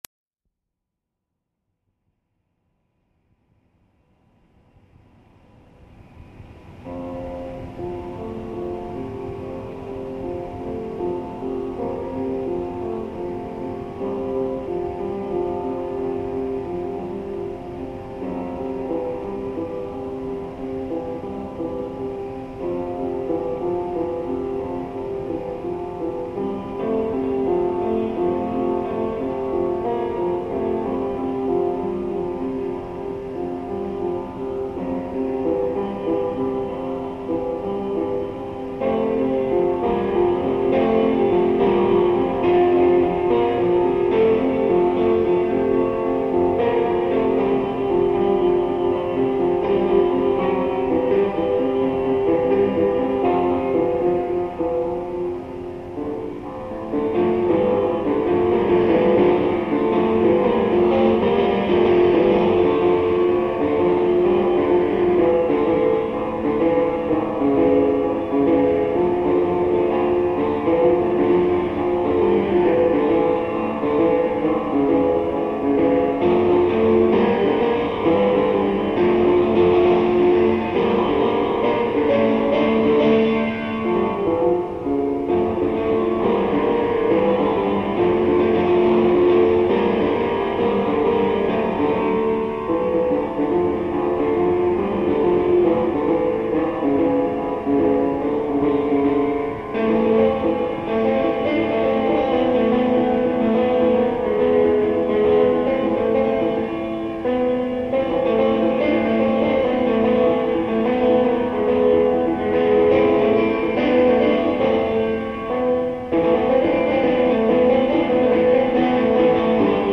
Live Piano Compositions
These exciting recordings are taken off of a low quality tape I recorded with a simple recorder at age 15 to archive some of my very first compositions.